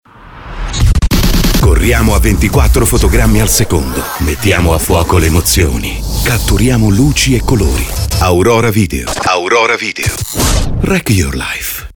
Lavoro anche in home studio (microfono Neumann Tlm 49, interfaccia Motu UltraLite-MK3 Hybrid, ambiente insonorizzato).
Sprechprobe: Werbung (Muttersprache):